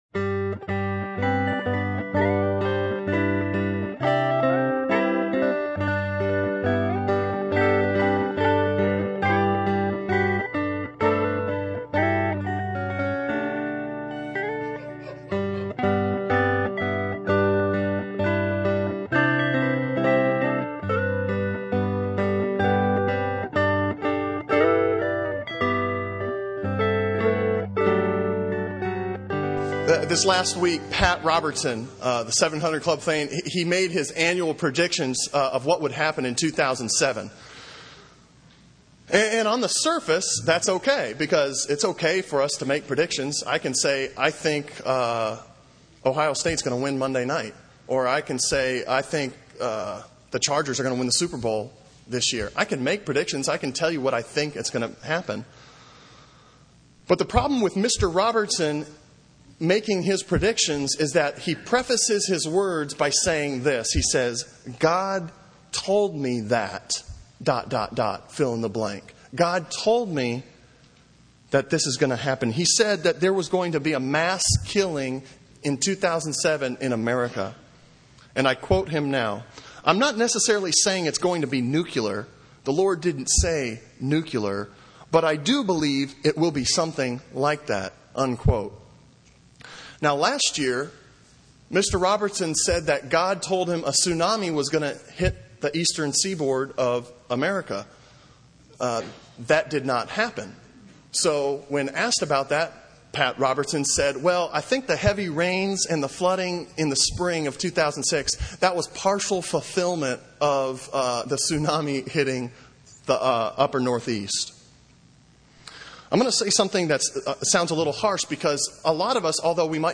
Sermon Audio from Sunday
Sermon on Isaiah 6:1-7 from January 7